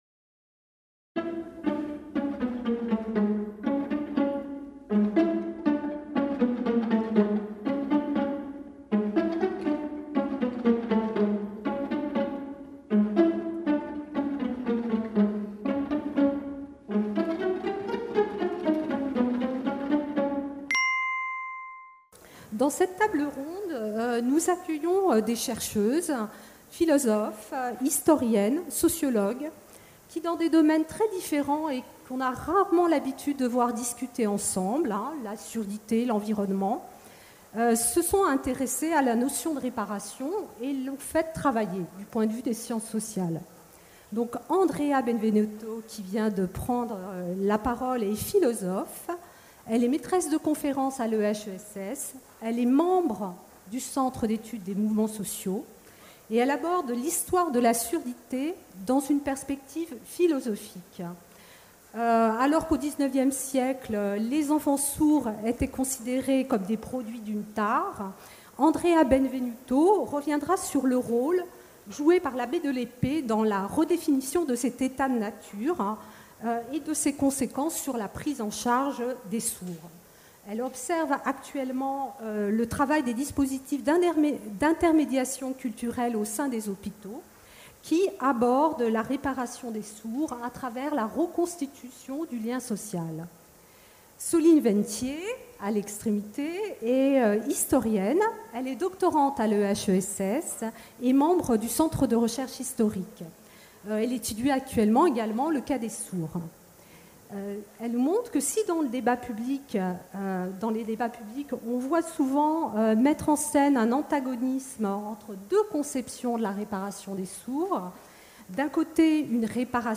Réparer la nature - Festival ALLEZ SAVOIR | Canal U